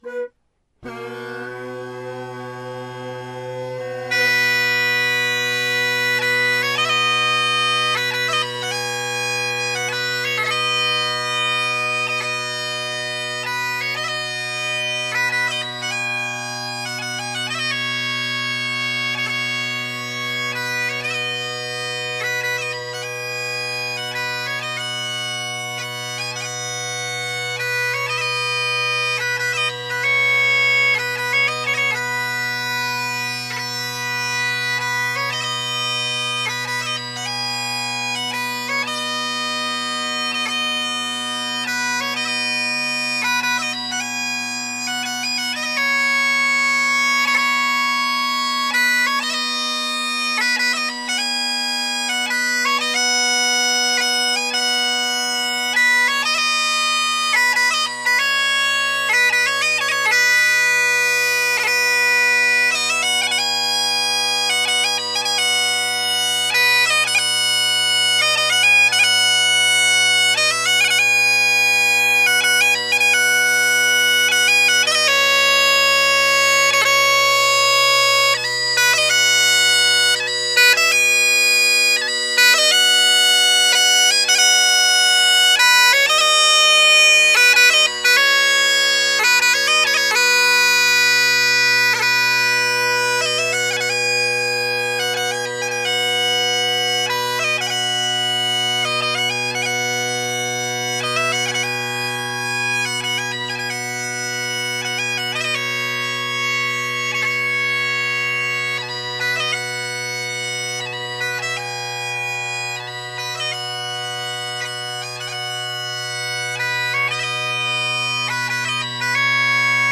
Great Highland Bagpipe Solo
Kids are sleeping and I’m in my walk-in closet with my Gellaitry pipes and my poly Sinclair chanter I just got back from being borrowed. Stick a MacLellan reed in there with Kinnaird drone reeds, tune them up and just play, with a Zoom H2 sitting on a box a few inches off the carpeted floor. by the closed door.
I’m going around in circles, well more like rotating, so the chanter drone balance will change as the set progresses. Yeah, I suck at playing birls, and blowing steady, among many other things.
waltzswallow.mp3